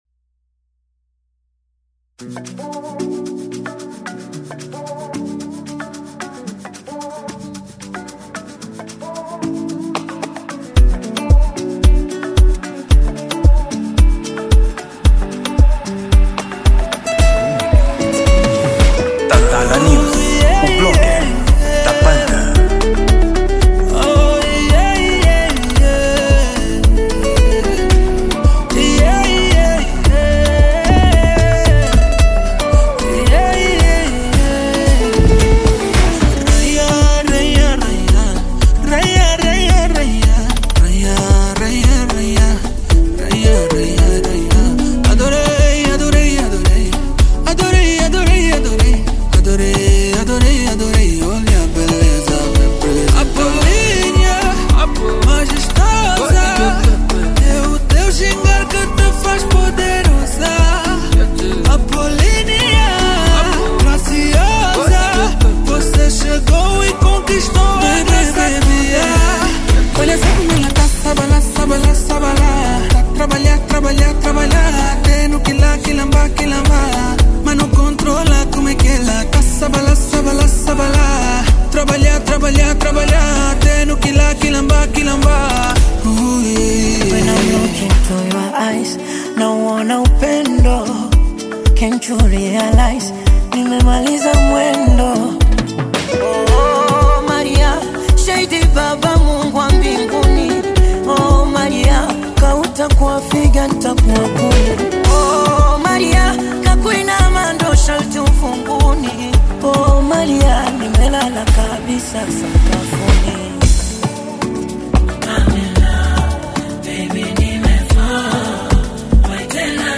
Género: Afro Pop